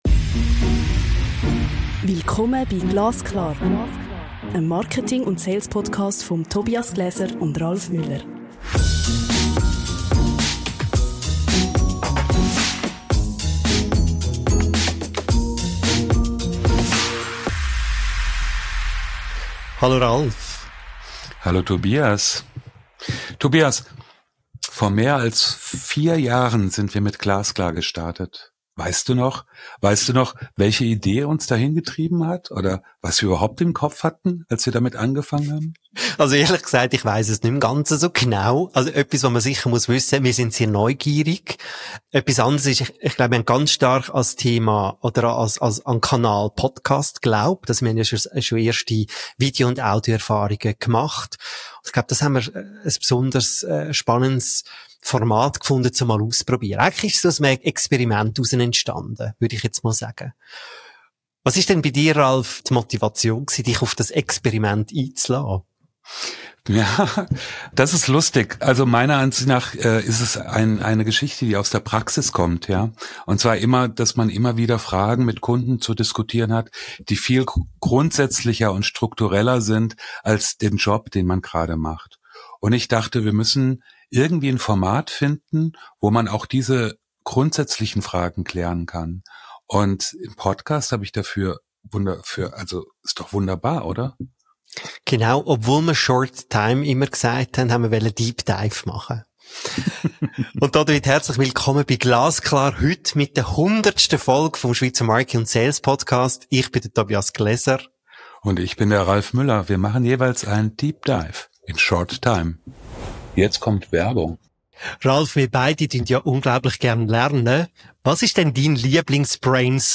Du kommst ins Studio.